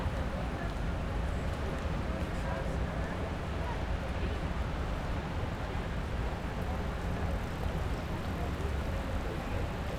Environmental
UrbanSounds
Streetsounds
Noisepollution